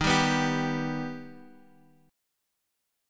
D# Augmented
D#+ chord {x 6 5 4 x 3} chord
Dsharp-Augmented-Dsharp-x,6,5,4,x,3.m4a